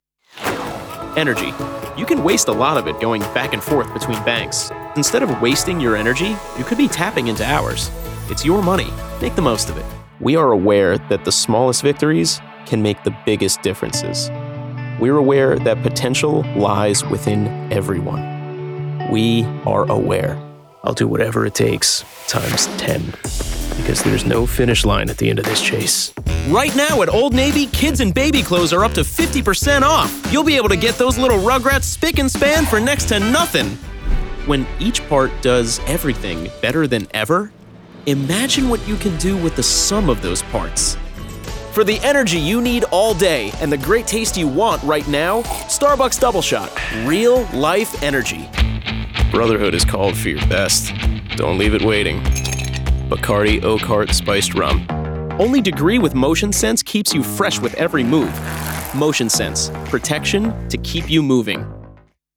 Commercial Demo
American (Generic and Regional)
Middle Aged